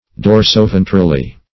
dorsoventrally.mp3